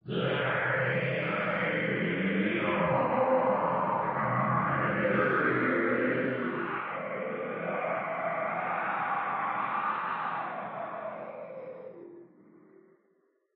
Здесь вы найдете саундтреки, фоновые шумы, скрипы, шаги и другие жуткие аудиоэффекты, создающие неповторимую атмосферу ужаса.
Звук ужасающего крика в игре Маленькие кошмары - Смерть Уборщика - Вопль